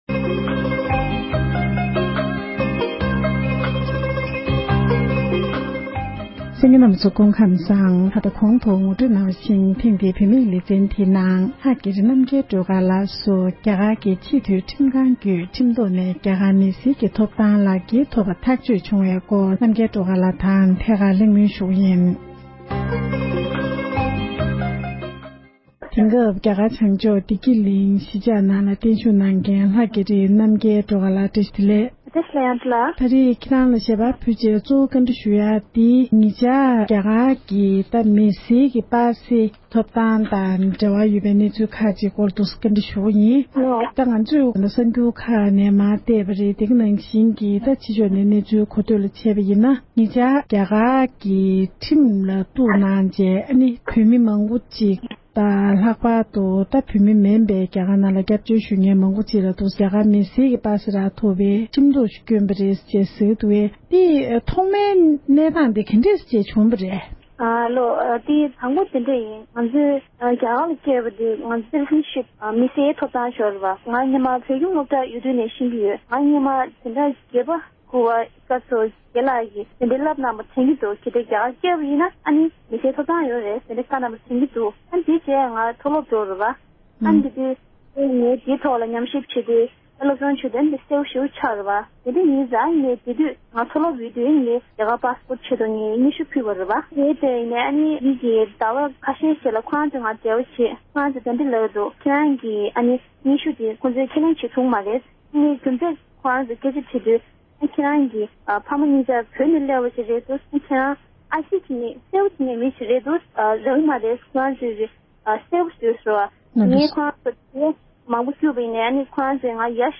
ཁོང་ལ་འབྲེལ་ཡོད་གནས་འདྲི་ཞུས་པར་གསན་རོགས༎